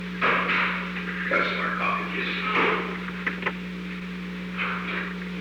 Secret White House Tapes
Conversation No. 538-9
Location: Oval Office
The President met with an unknown person